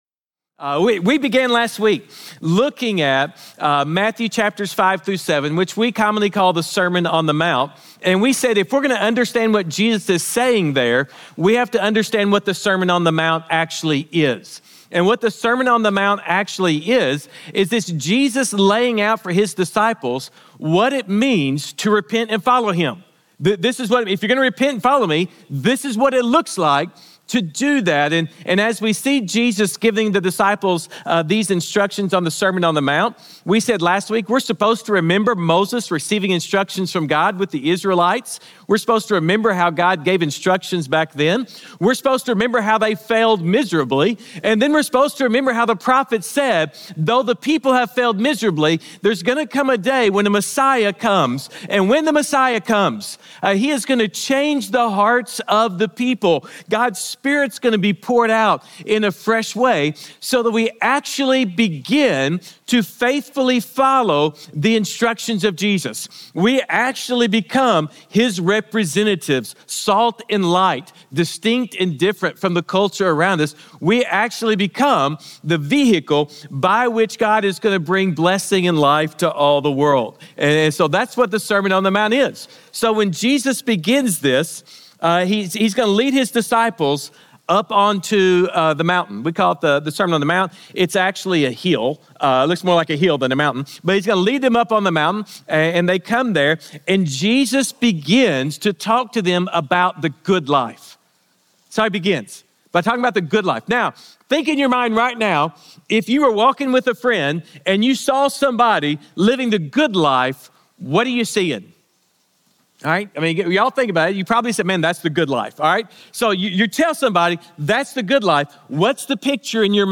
Sermons | Grace Community Church